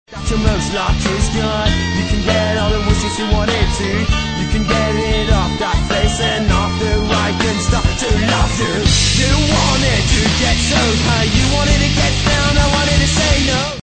neo métal